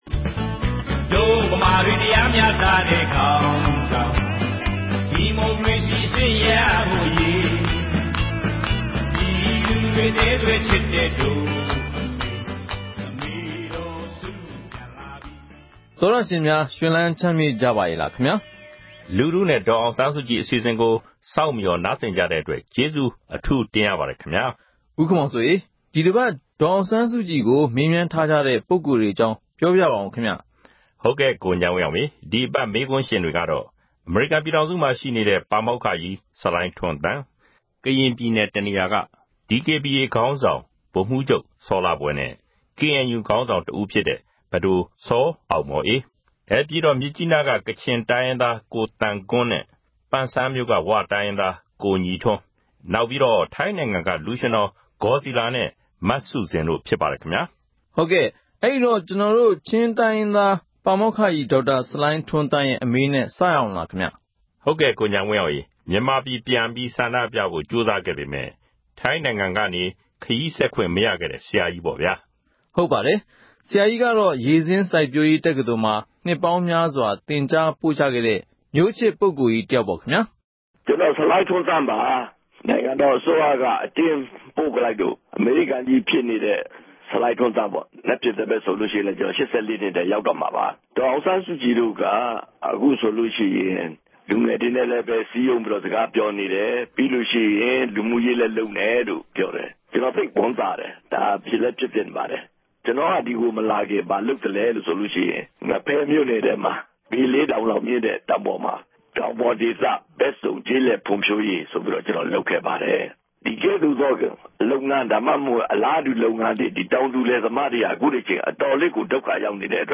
‘လူထုနှင့် ဒေါ်အောင်ဆန်းစုကြည်’ အပတ်စဉ်အမေးအဖြေ
ဒီတပတ် လူထုနဲ့ ဒေါ်အောင်ဆန်းစုကြည် အစီအစဉ်မှာ `ကျမတို့ကတော့ ဒီမိုကရေစီနဲ့ လူ့အခွင့်အရေးရဖို့ ကြိုးပမ်းတဲ့ နေရာမှာ အကြမ်းမဖက်ပဲလုပ်မယ်လို့ သန္နိဋ္ဌာန် ချထားပါတယ်၊ ဒါက အကြမ်းမဖက်တဲ့လမ်းက လွယ်ကူလို့ မဟုတ်ပါဘူး။ မြန်မာနိုင်ငံမှာ နိုင်ငံရေးအပြောင်းအလဲတွေကို အကြမ်းဖက်တဲ့နည်း၊ လက်နက်နည်းနဲ့ လုပ်မှ ဖြစ်မယ်ဆိုတဲ့ အမြင်ကို ပြောင်းလဲချင်လို့ပါ` ဆိုတဲ့ ဒေါ်အောင်ဆန်းစုကြည်ရဲ့ စကားကို နားဆင်ရမှာ ဖြစ်ပါတယ်။